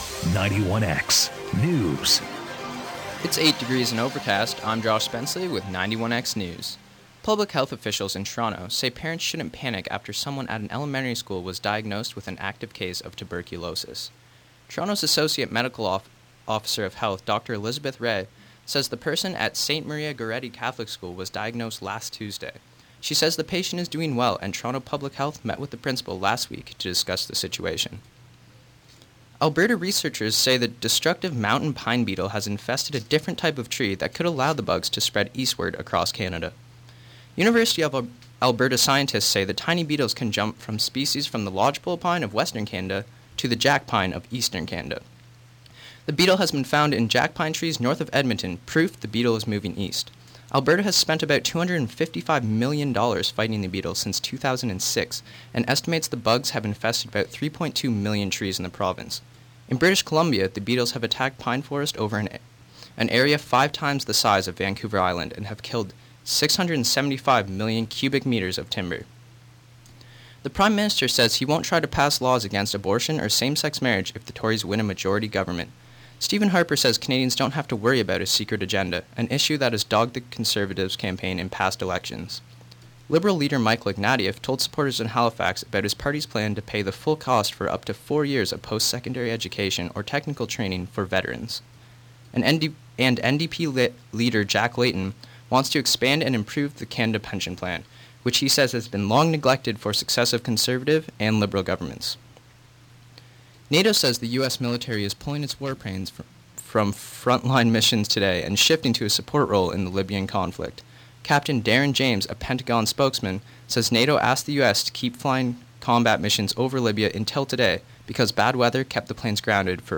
91x news Monday April 4, 2011 3 p.m.